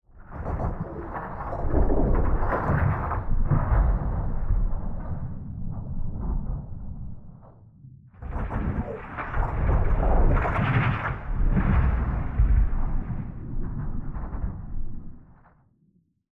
extraction and excavation channel noises
Rumble.mp3